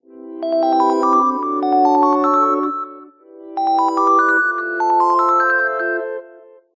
Catégorie: Messages - SMS